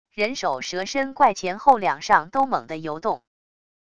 人首蛇身怪前后两上都猛的游动wav音频